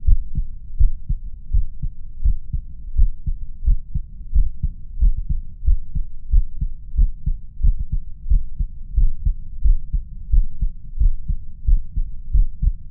heart beat
beat heart heartbeat sound effect free sound royalty free Voices